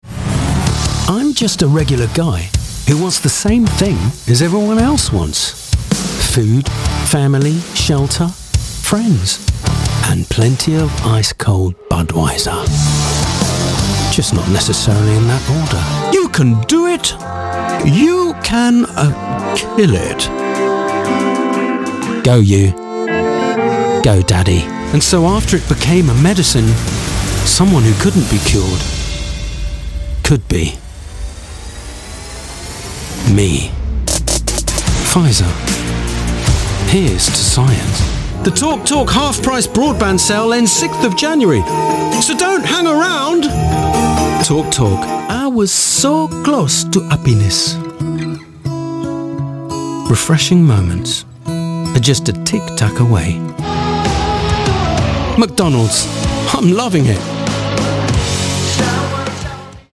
Styles: Friendly/Upbeat, Corporate, Sensitive/Reassuring, Natural/Unaffected, Engaging, Sophisticated, /Posh Sexy.
Accents: Neutral, RP, London, Cockney, Posh, French
► Character Reel 60sec – Budweiser, Go Daddy, Pfizer, Talk Talk, Tic Tac, MacDonald’s.
Sound Proofed Studio